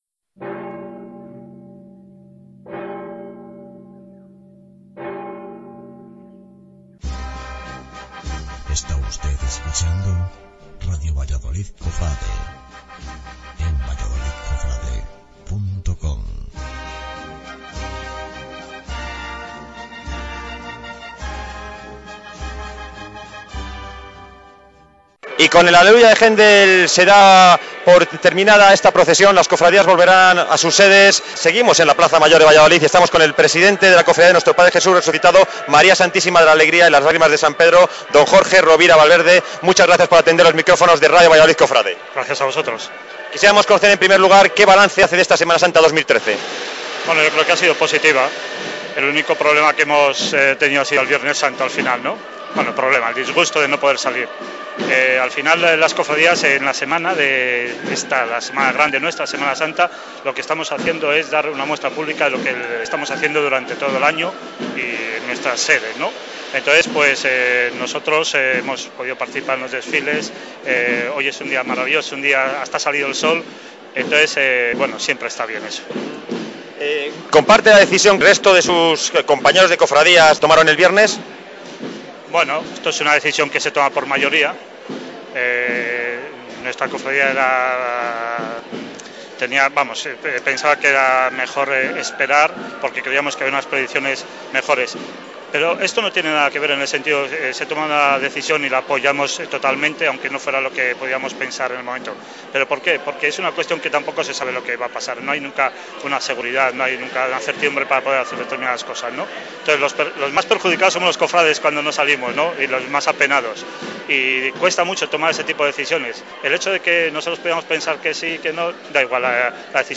entrevistaresucitado.mp3